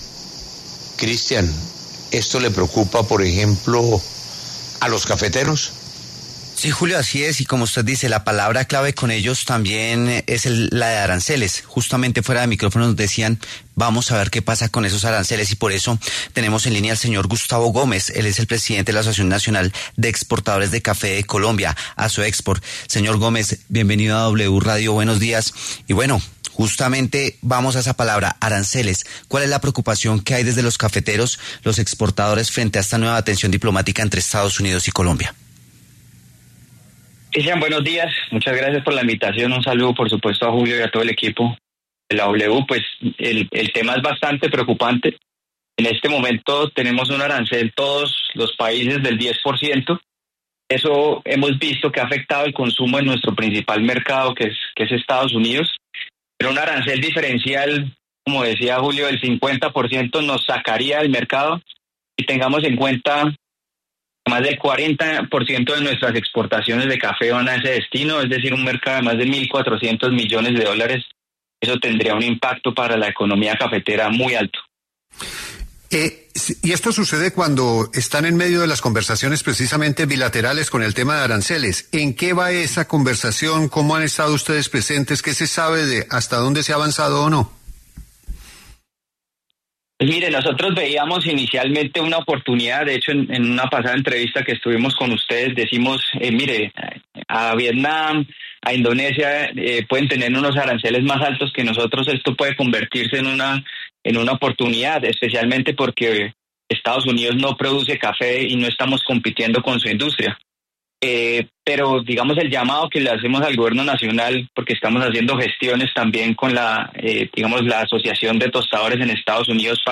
En los micrófonos de La W